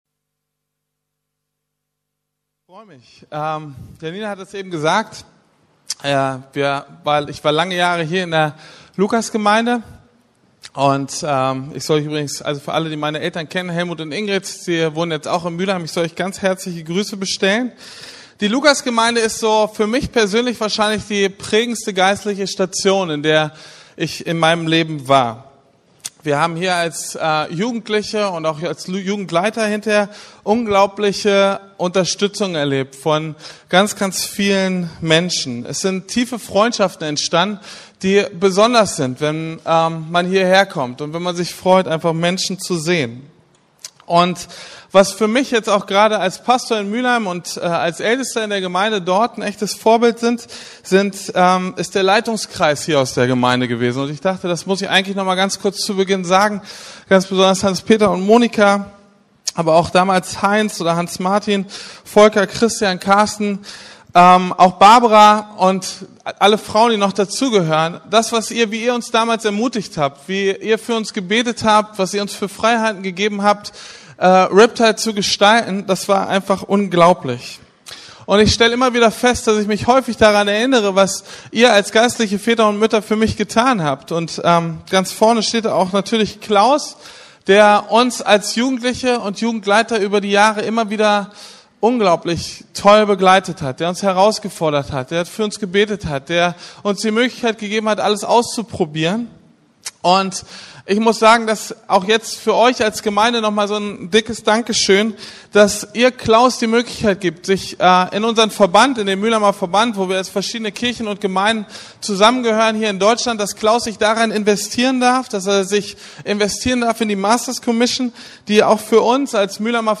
Gott erleben ~ Predigten der LUKAS GEMEINDE Podcast